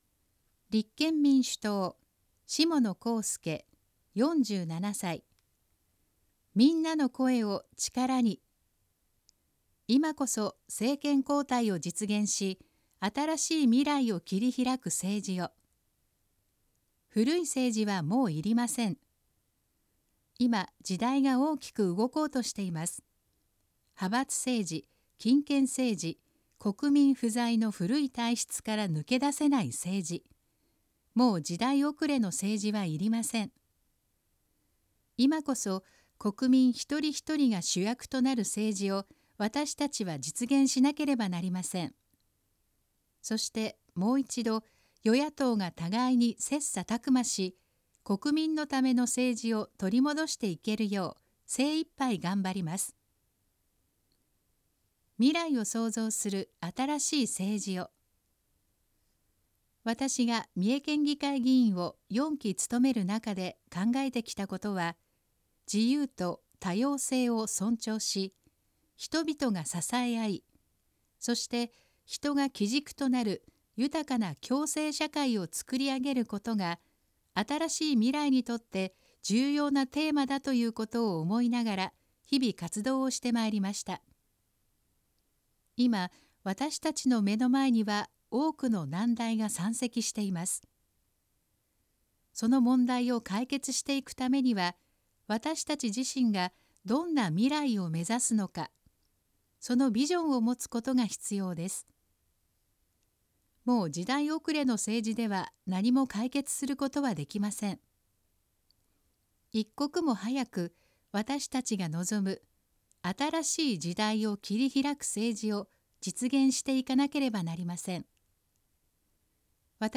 選挙公報の音声読み上げ対応データ（候補者提出）